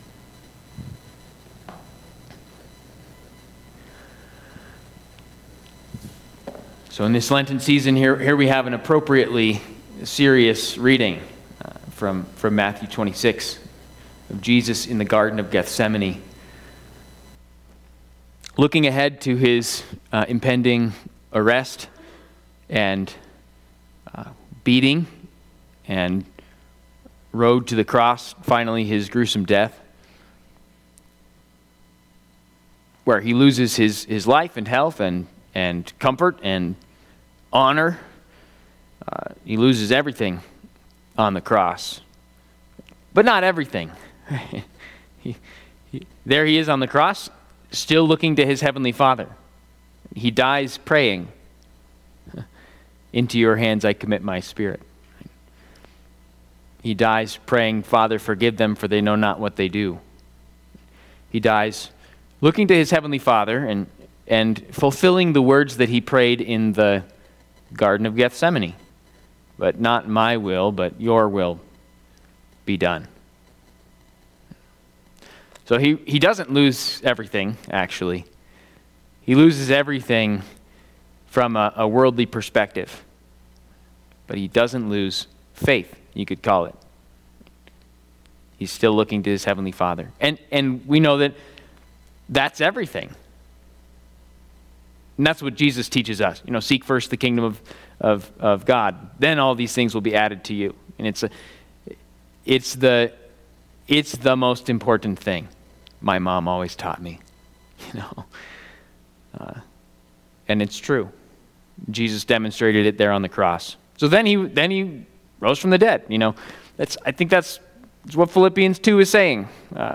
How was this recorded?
Trinity Lutheran Church, Greeley, Colorado Thy Will Be Done...Give Us Our Daily Bread Mar 19 2025 | 00:18:41 1x 00:00 / 00:18:41 Subscribe Share RSS Feed Share Link Embed